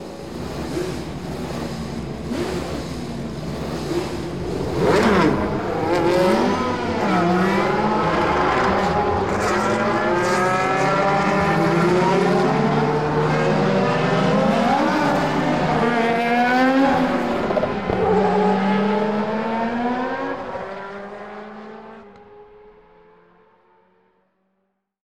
starting grit usa racing.mp3